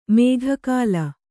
♪ mēgha kāla